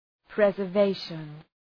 {,prezər’veıʃən}